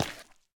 resin_step4.ogg